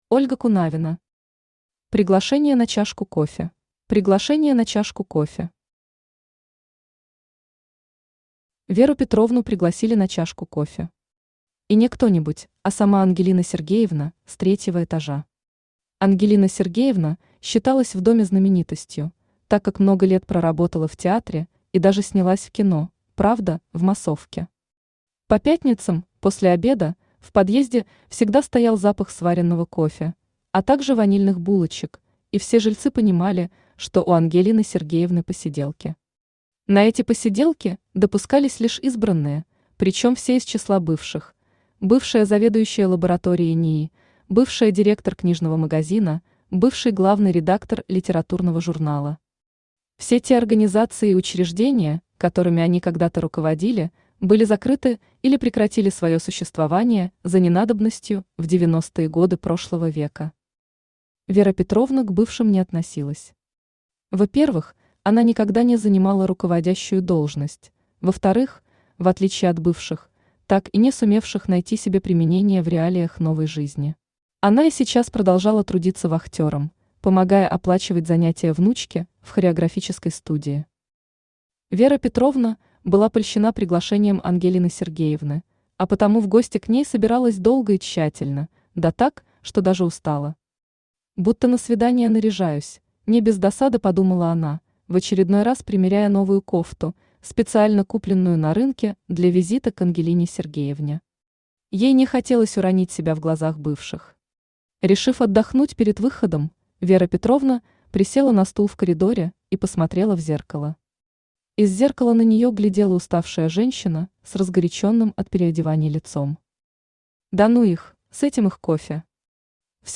Аудиокнига Приглашение на чашку кофе | Библиотека аудиокниг
Aудиокнига Приглашение на чашку кофе Автор Ольга Кунавина Читает аудиокнигу Авточтец ЛитРес.